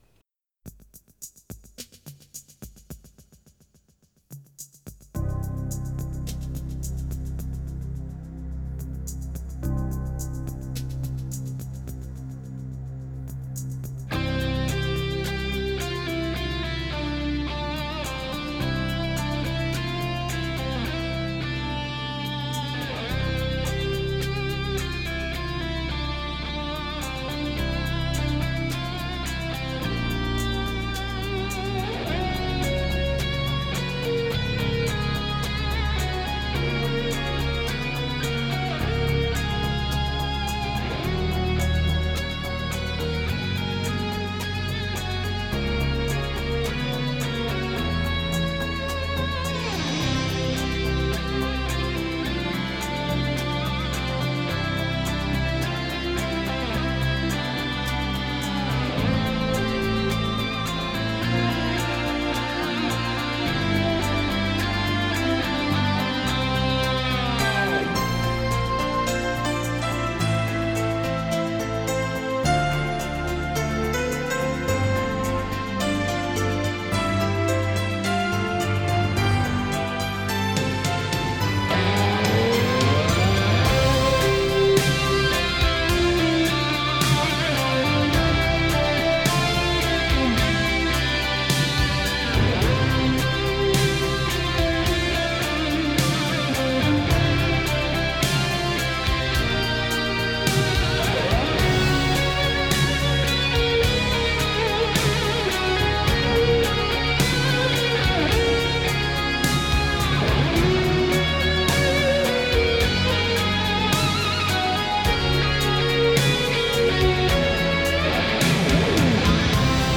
USS Forrestalin tarina on saatavissa kuunnelmana .